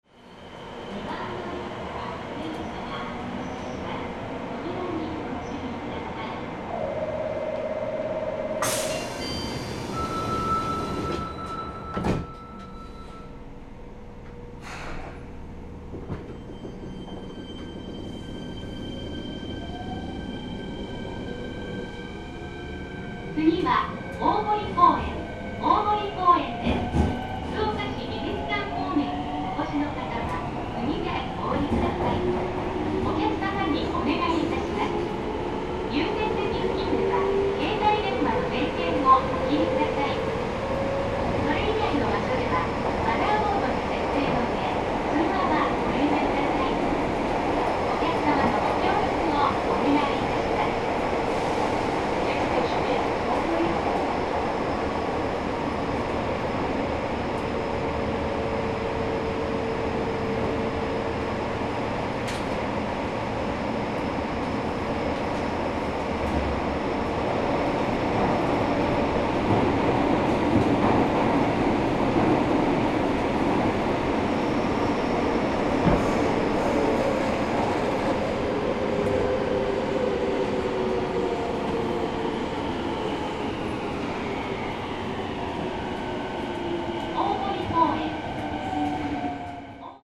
鉄道走行音ＣＤ 真栄館［既刊情報・福岡市交通局1000N系後期更新車 空港・ＪＲ筑肥線 普通］
録音車両：１０３２
今回のＣＤは、福岡市営地下鉄開業時に投入された電機子チョッパ車1000系のVVVF後期更新車で録音しました。後期更新車特有の全電気ブレーキの走行音をお楽しみください。
Fukuoka1000N.mp3